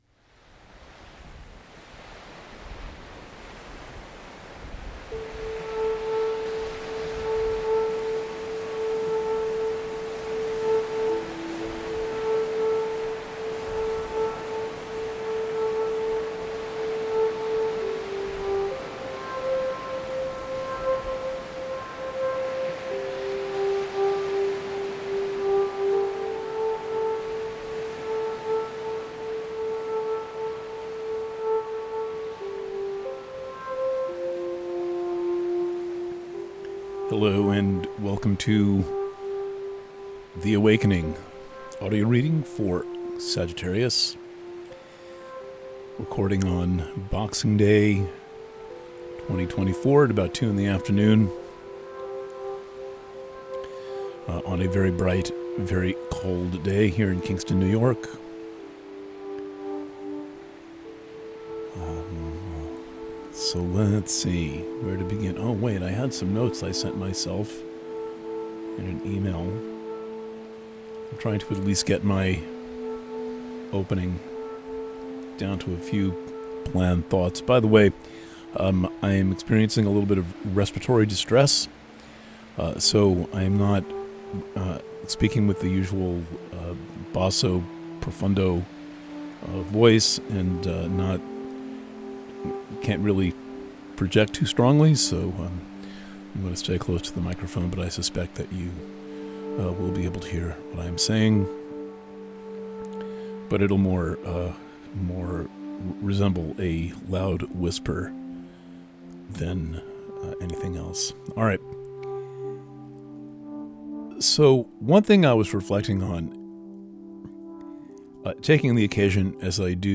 Preview – The Awakening for Sagittarius Purchasing options for The Awakening Preview – Written reading Preview – The Awakening for Sagittarius – PDF Preview – Audio reading Alternate Player (Audio Only) Views: 94